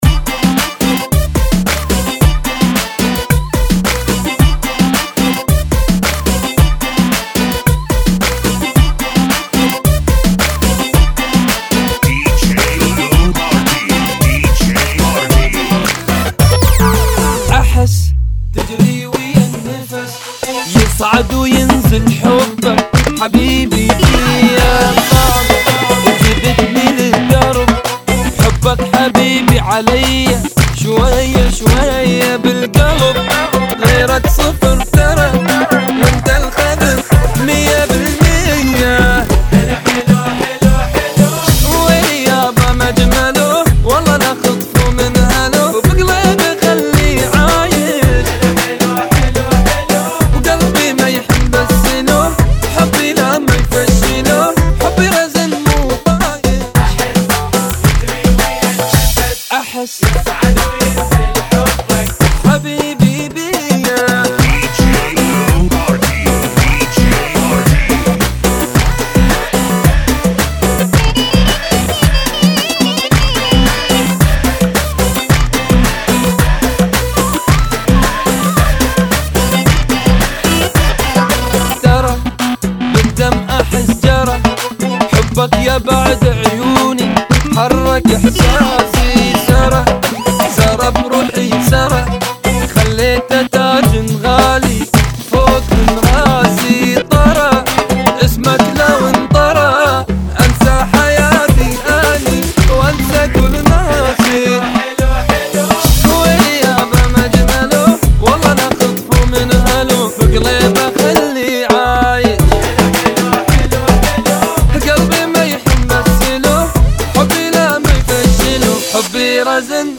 Funky ( Bbm 110